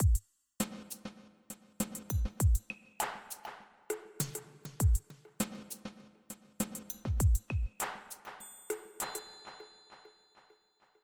Я взял «трясучку» типа маракас (shaker), треугольник (triangle), приглушённый треугольник (muted triangle), clavinet (без понятия как это будет по-русски) и деревянную палочку (wood block).
К шейкеру, треугольнику и clavinet задал банальную реверберацию, а деревянной палочке и приглушённому треугольнику кроме ревербератора добавил эффект задержки (delay).
Ну и, наконец, треугольник я поставил в конце 4 такта для усиления всей ритмической фигуры.